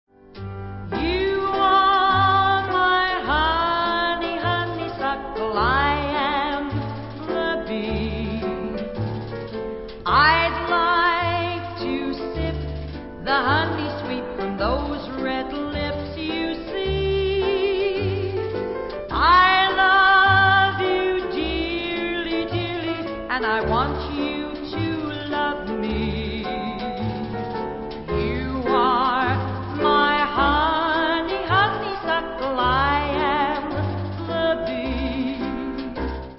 and the Bee", a popular music hall tune of the day.